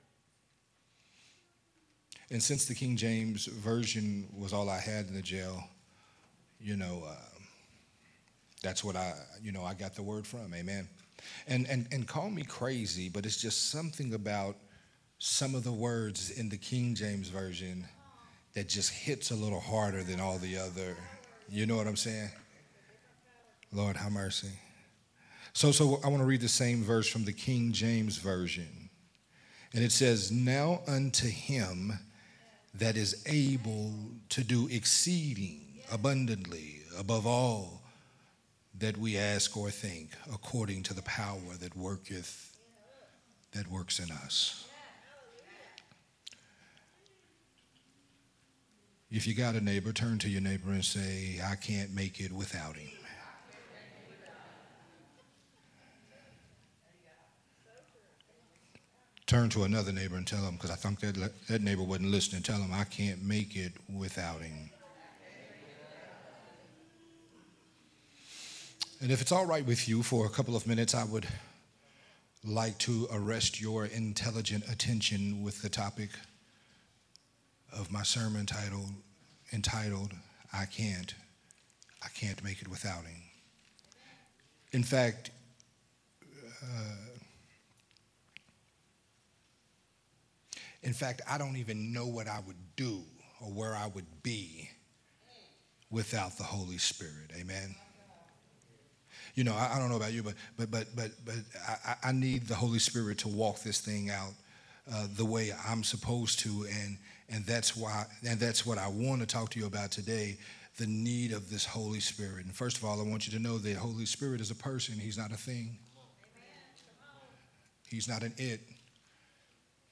Sunday Morning Worship Sermon
Sunday Morning Worship sermon
recorded at Unity Worship Center on July 7